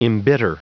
Prononciation du mot embitter en anglais (fichier audio)
Prononciation du mot : embitter